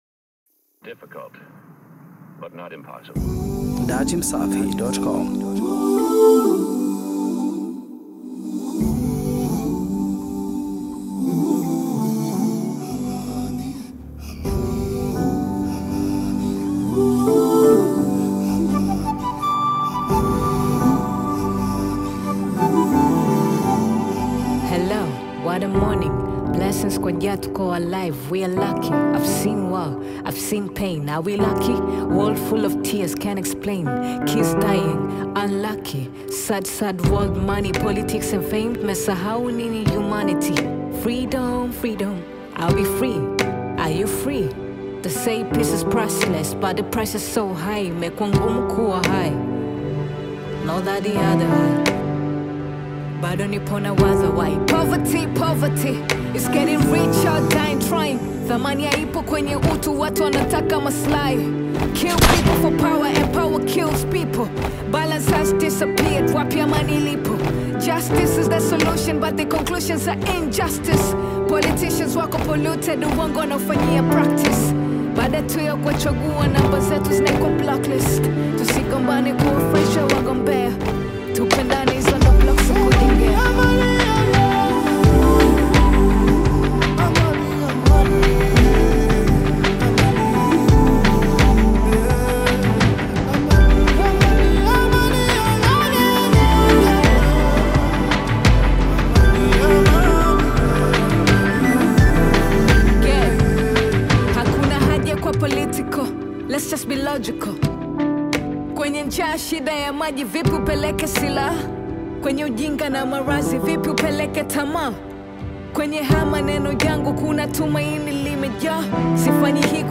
Bongo Flavour